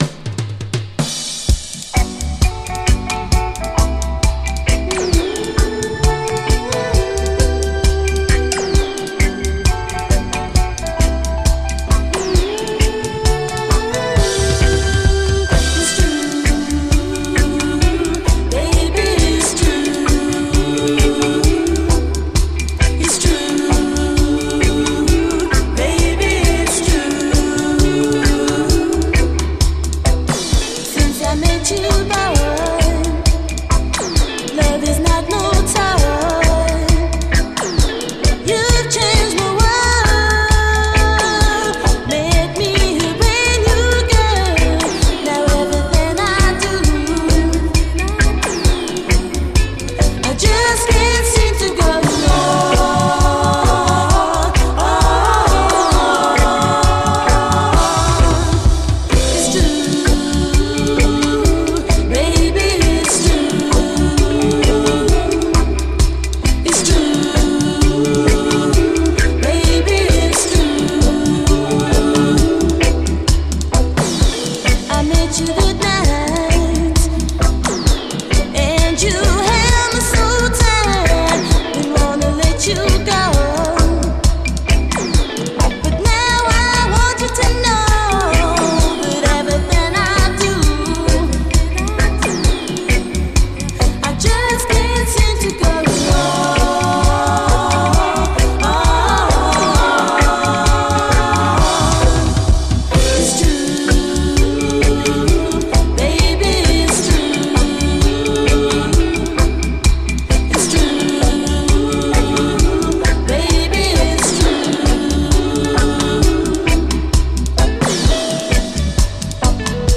REGGAE
両面最高UKラヴァーズ！
どちらも後半はダブに接続。